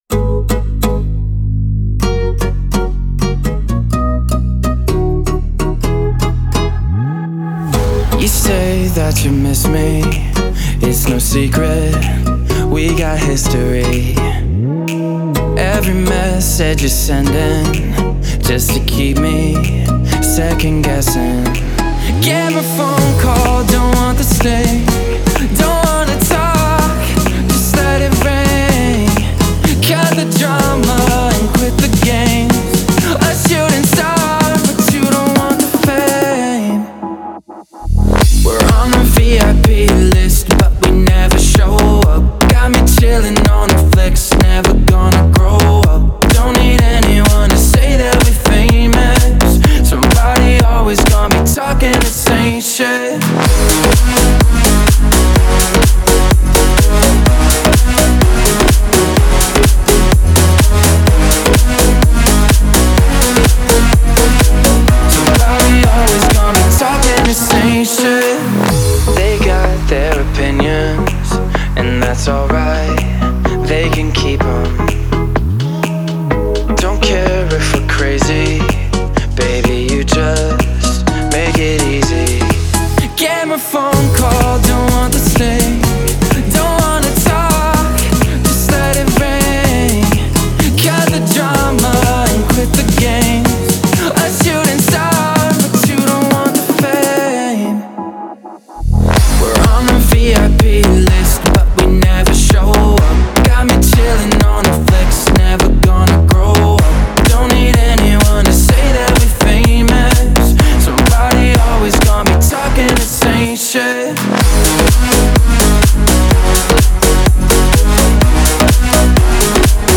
это зажигательная трек в жанре электроника